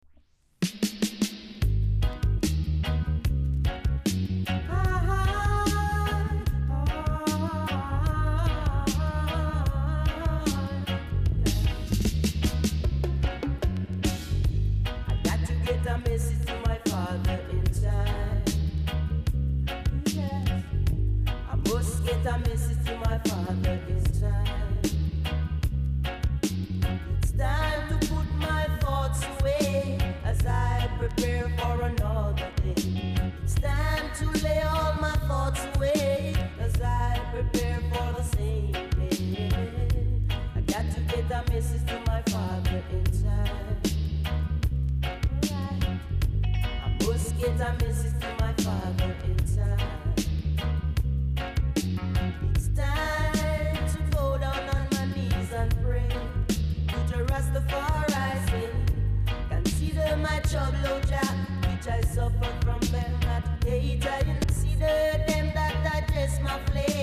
※小さなチリノイズが少しあります。